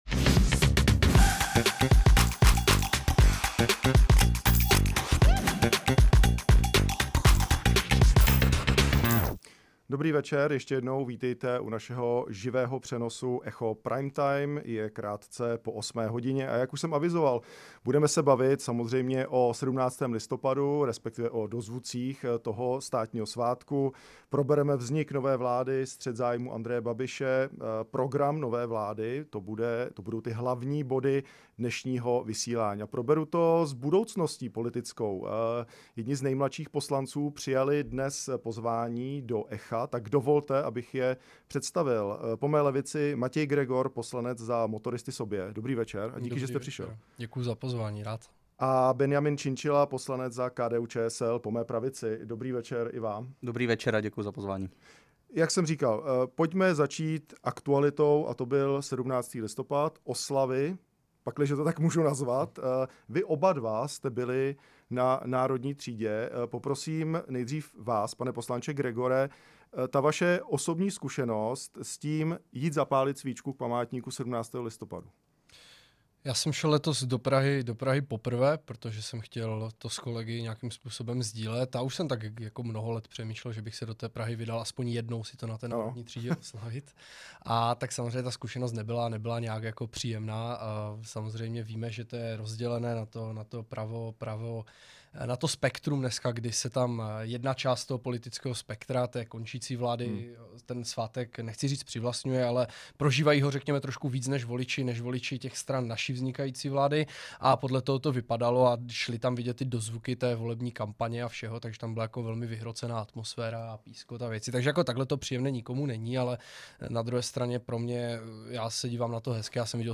Sváteční Echo Prime Time přinesl debatu nových mladých poslanců Matěje Gregora (Motoristé sobě) a Benjamina Činčily (KDU-ČSL), kteří se střetli především o podobě státního rozpočtu pro příští rok. Zatímco Činčila kritizoval plánované výdaje vlády Andreje Babiše, Gregor upozorňoval na zdržované poslání návrhu do Poslanecké sněmovny. Řeč byla i o střetu zájmů Andreje Babiše, aktivitě prezidenta Petra Pavla i stavu veřejných financí.